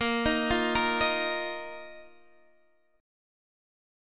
Bbsus4 chord